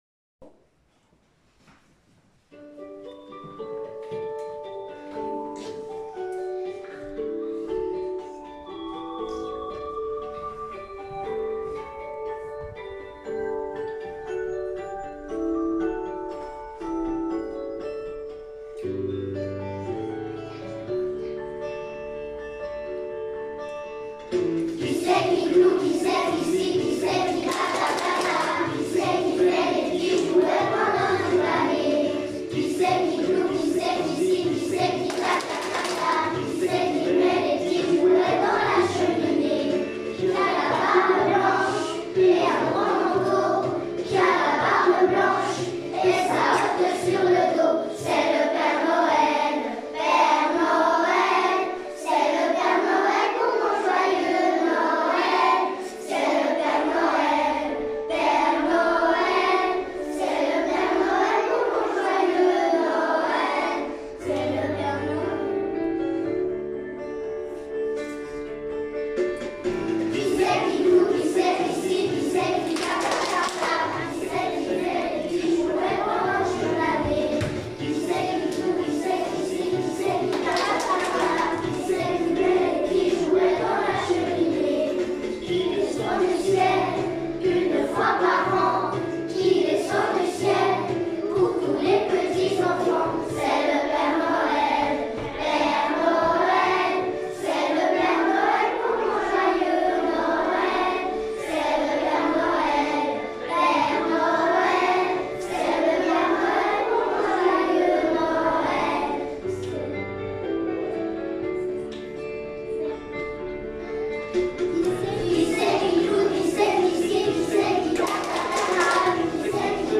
2021-22 : “Chantée de Noël”, les classes de Corcelles
Groupe 4 : classes 1-2P41, 5P43 et 3P41